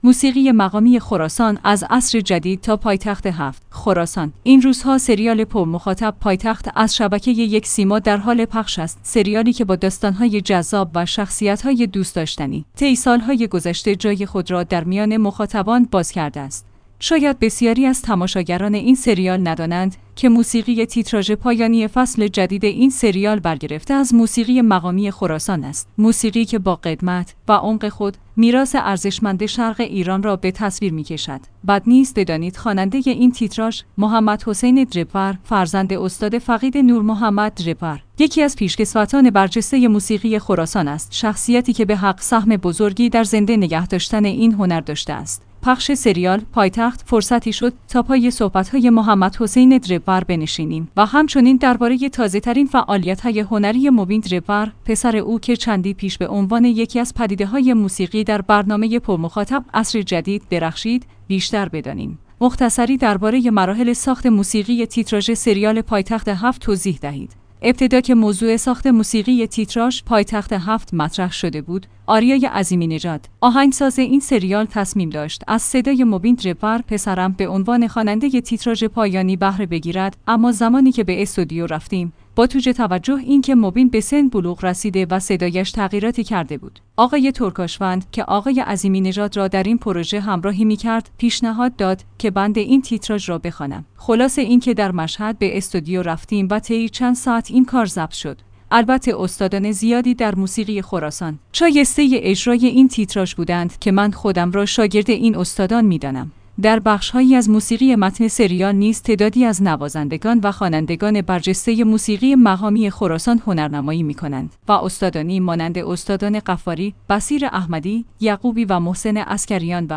موسیقی مقامی خراسان؛ از «عصر جدید» تا «پایتخت ۷»